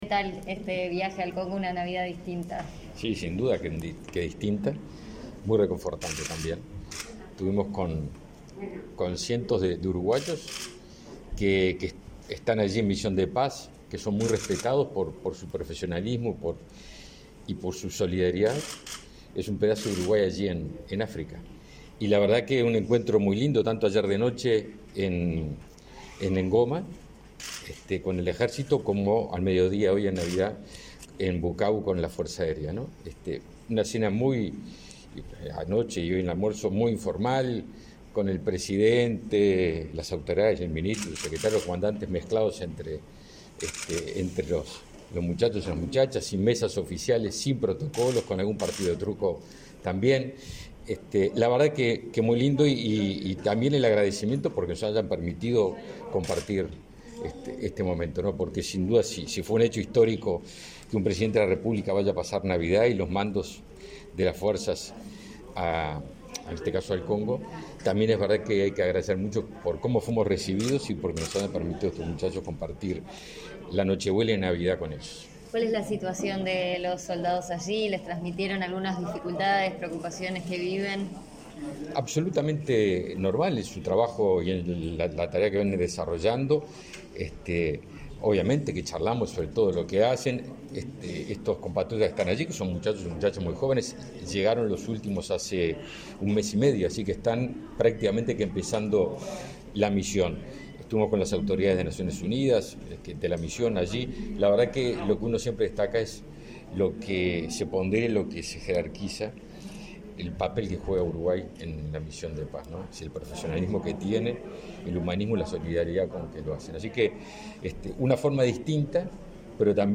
Declaraciones a la prensa del ministro Javier García
El ministro de Defensa, Javier García, dialogó con la prensa este domingo 26, luego de regresar al país tras su visita al Congo, acompañando al